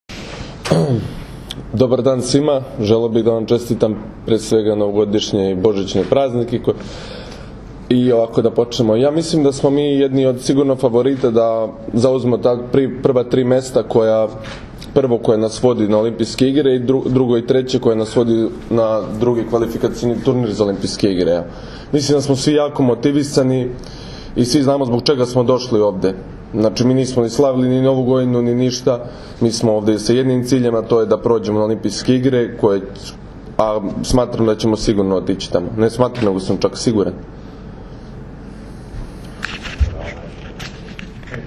Tim povodom danas je u beogradskom hotelu “M” održana konferencija za novinare, kojoj su prisustvovali Nikola Grbić, Dragan Stanković, Marko Ivović i Uroš Kovačević.
IZJAVA UROŠA KOVAČEVIĆA